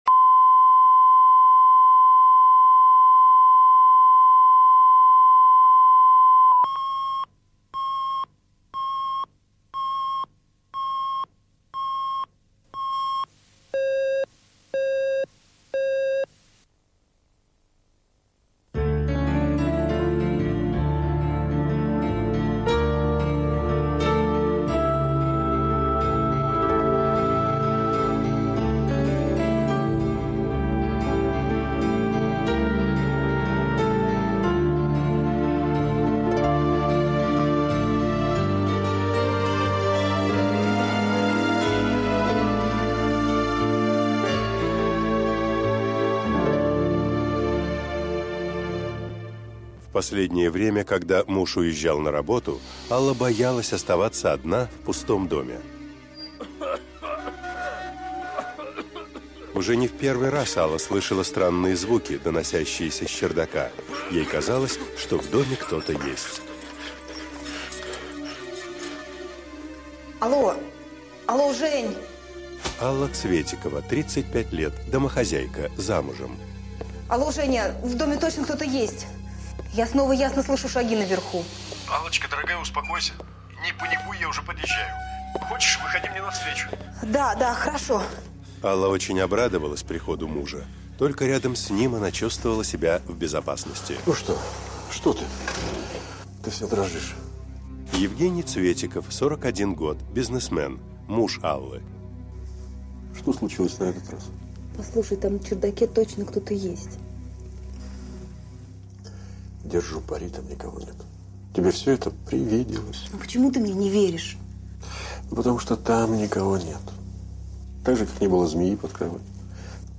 Аудиокнига Чужие в доме | Библиотека аудиокниг
Прослушать и бесплатно скачать фрагмент аудиокниги